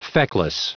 Prononciation du mot feckless en anglais (fichier audio)
Prononciation du mot : feckless